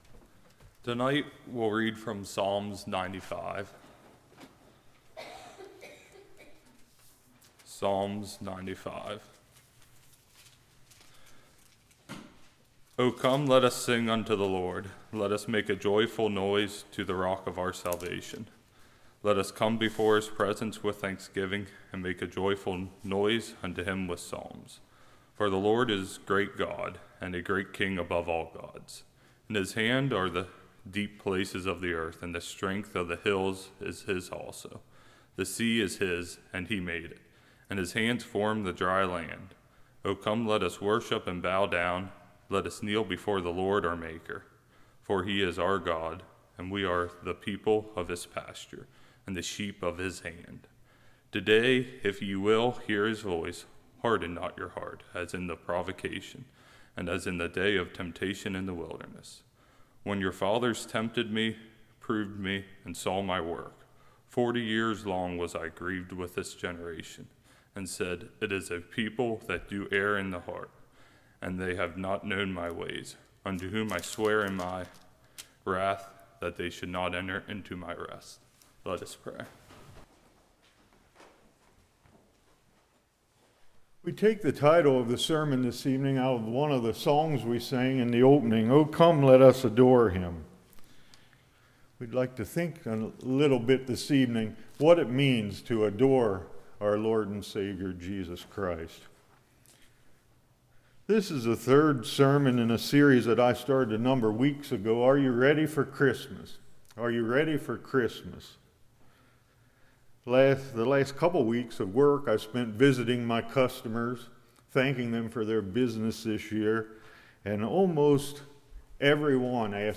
Psalms 95 Service Type: Evening Should Christians Celebrate Christmas?